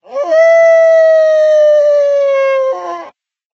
howl1.mp3